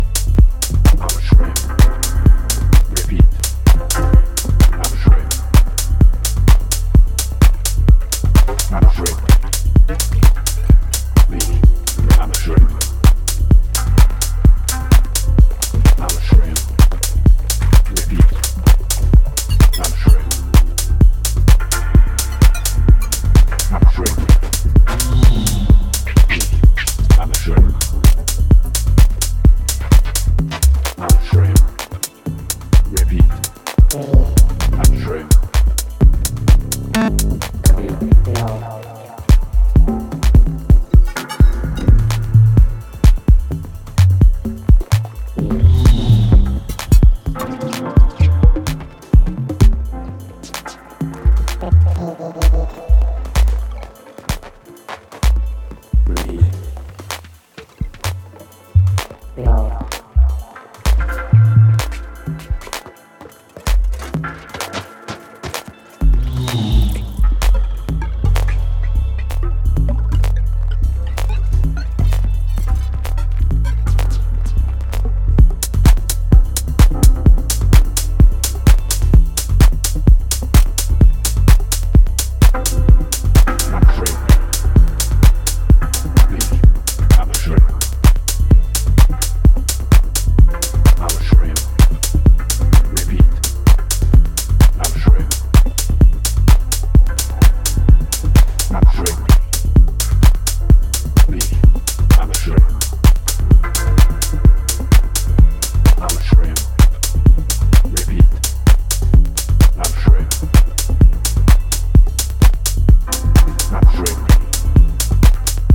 exquisitely crafted minimal dance music